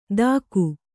♪ dāku